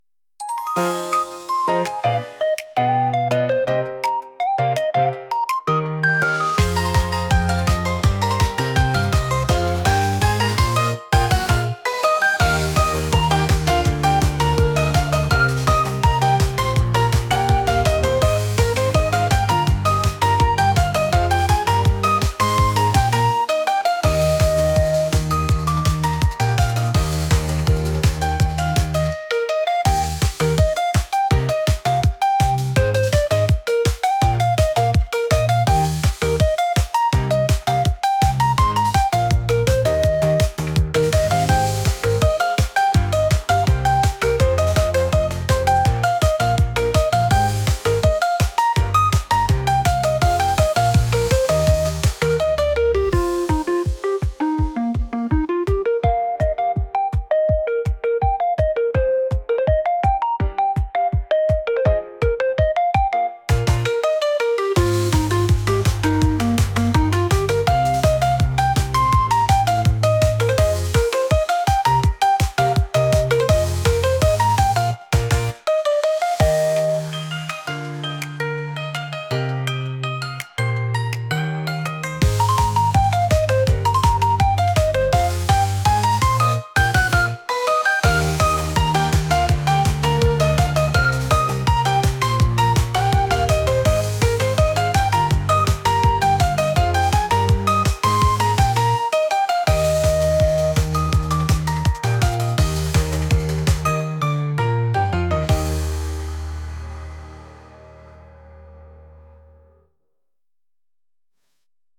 好きな人に会いに行くのにどんな服を着るのかが悩ましいけど楽しい、そんなイメージです。